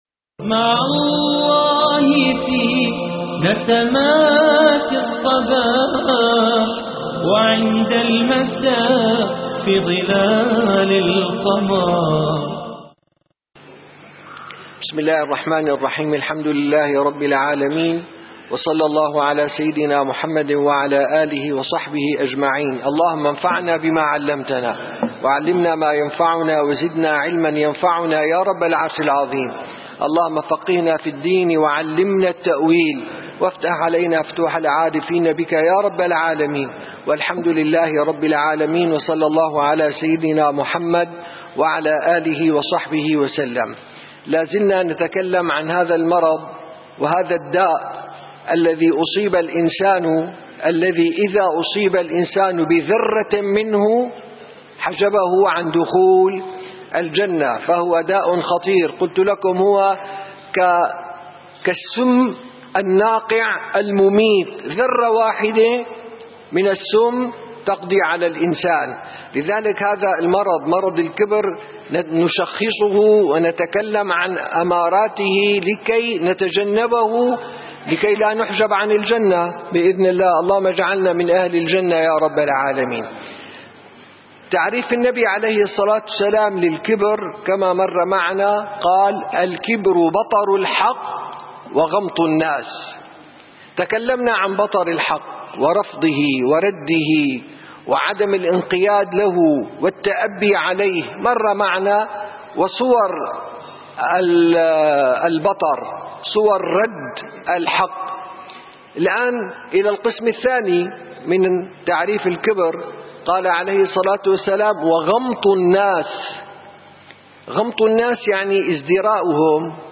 13 - درس جلسة الصفا: خطورة اذدراء النفس